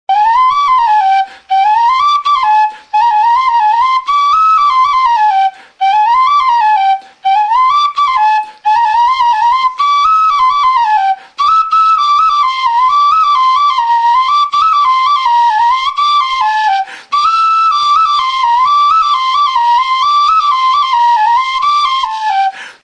JOTA.
TXILIBITUA
Aerófonos -> Flautas -> Recta (dos manos) + kena
Bi eskuko kanaberazko moko flauta zuzena da.